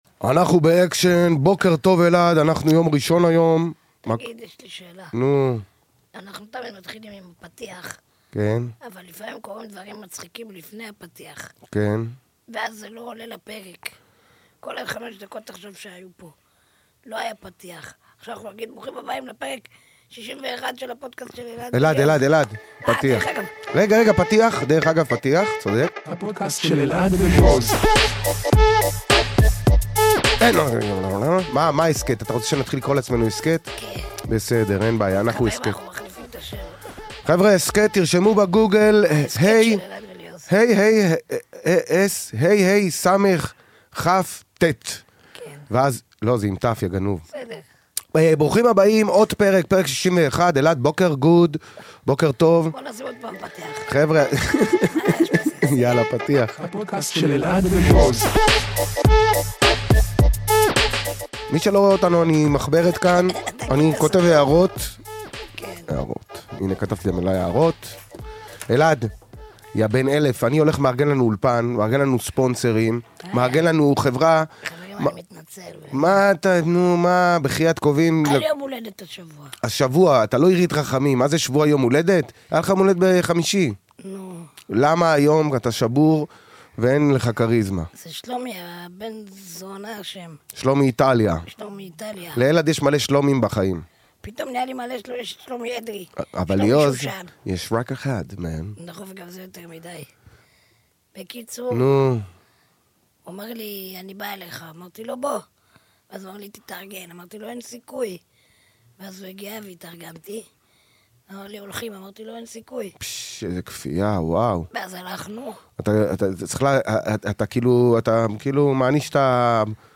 מצטלם,מוקלט ונערך באולפני all-in